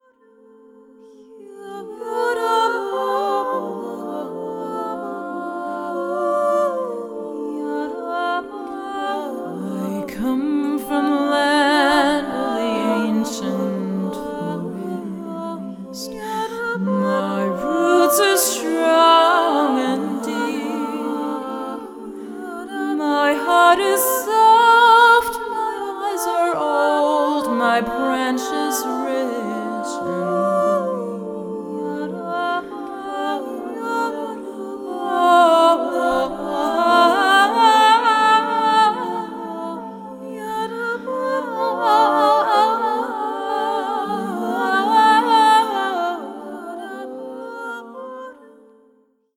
vocals
piano